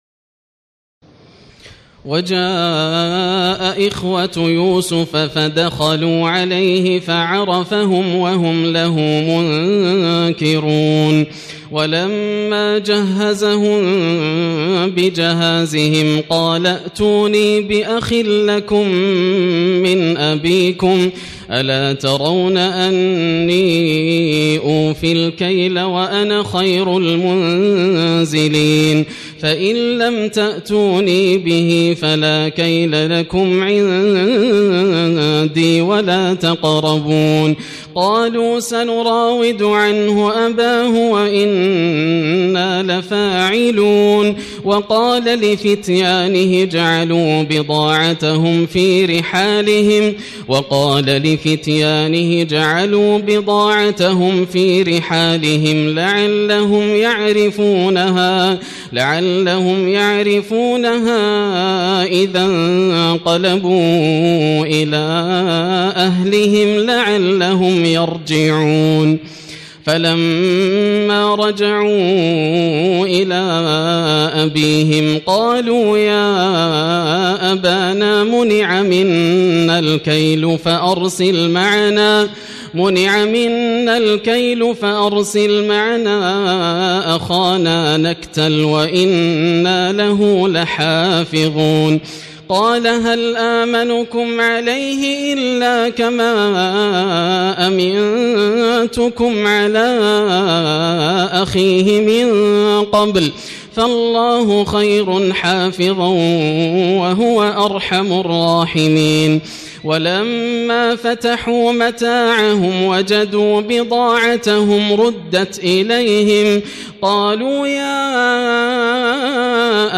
تراويح الليلة الثانية عشر رمضان 1436هـ من سورتي يوسف (58-111) و الرعد (1-18) Taraweeh 12 st night Ramadan 1436H from Surah Yusuf and Ar-Ra'd > تراويح الحرم المكي عام 1436 🕋 > التراويح - تلاوات الحرمين